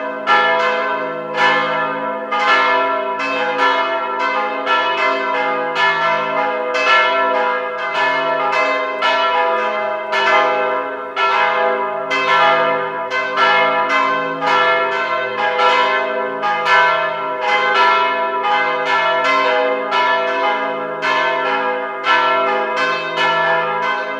You would have heard church bells ringing, in every Church, in ever European city.
mixkit-sanctuary-or-cathedral-big-bells-602.wav